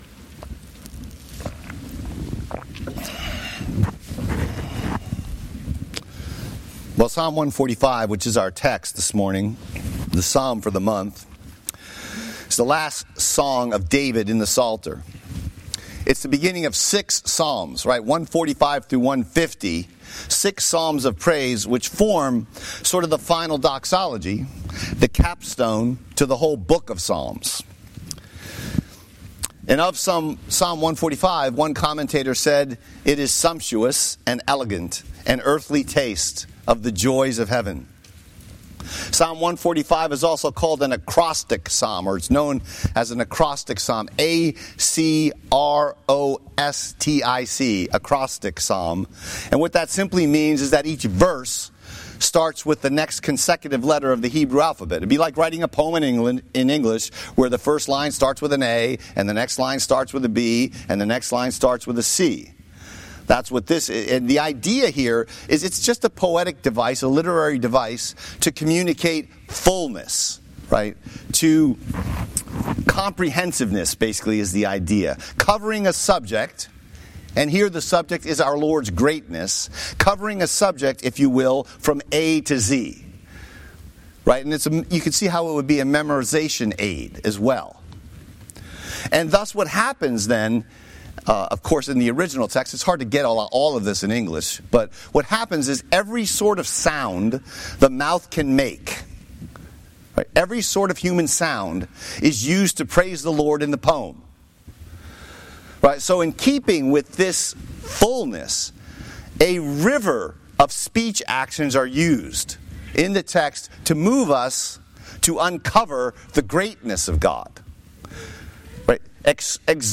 Sermon Text: Psalm 145:1-21